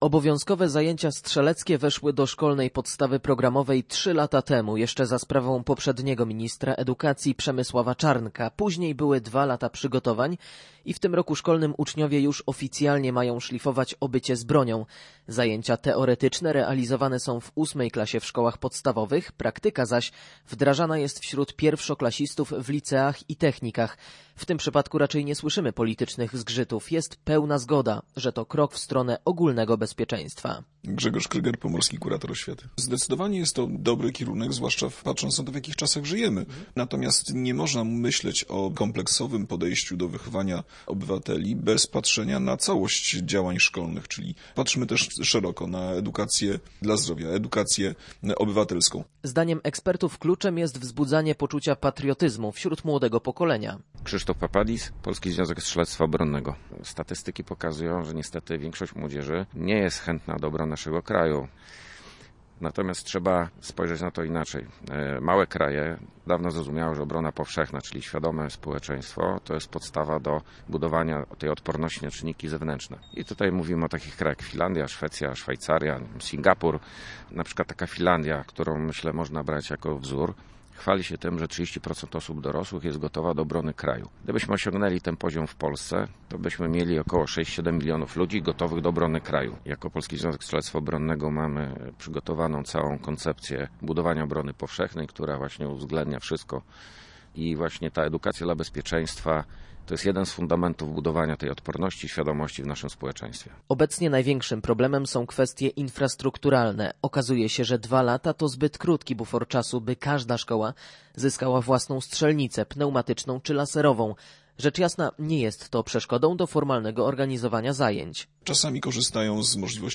Z tego powodu, jak wyjaśnia Grzegorz Kryger, pomorski kurator oświaty, w zdecydowanej większości odbywały się one na zewnętrznych strzelnicach.